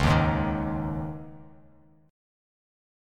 C#sus2 chord